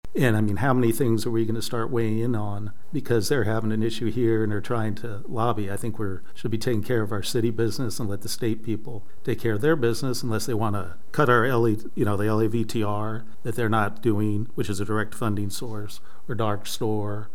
Commissioner John Matta says he thinks the city commission should stick to matters that affect city business and not get involved in the state’s partisan political battles.